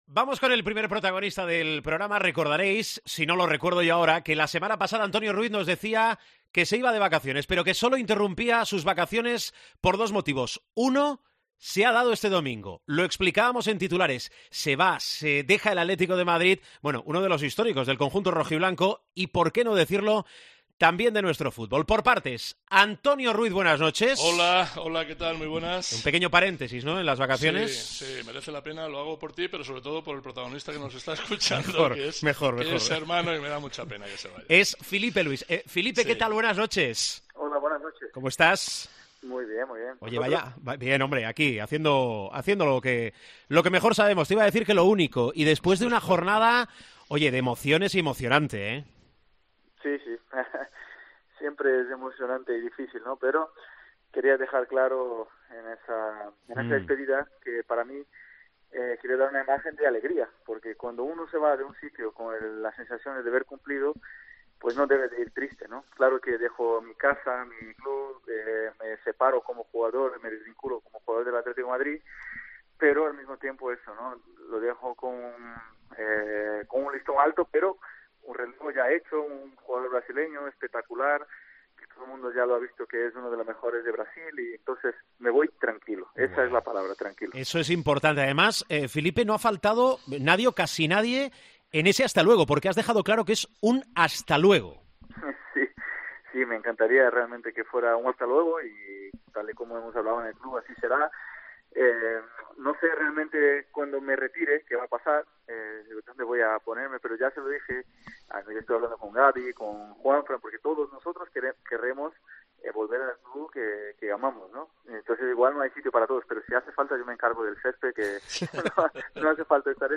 Filipe Luis ha atendido al Tiempo de Juego de COPE en el día de su despedida del Atlético de Madrid. El jugador se ha mostrado emocionado, y ha calificado de “difícil” la decisión.